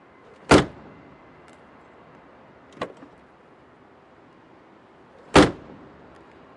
cardoor slam
描述：Closing a car door.Recorded in December 2006 on a Telefunken Magnetophon 301.
标签： car cardoor closing door slam slamming
声道立体声